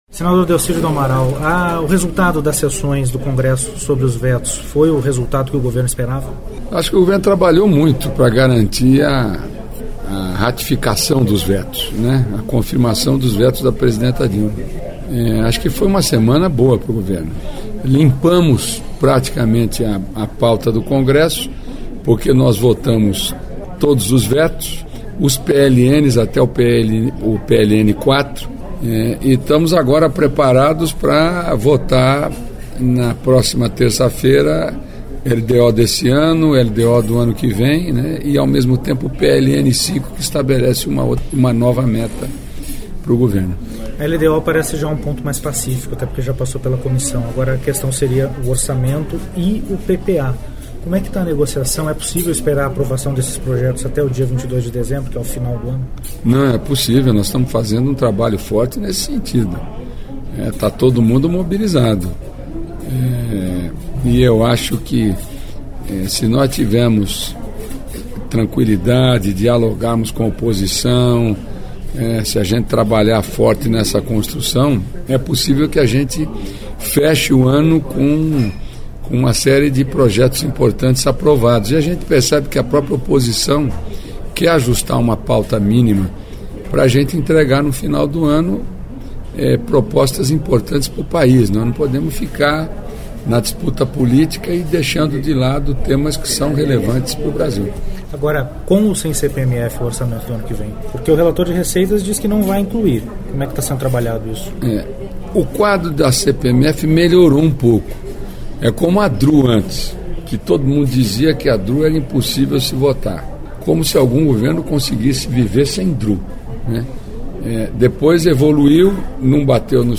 Senado em Revista: Entrevistas - 20/11/2015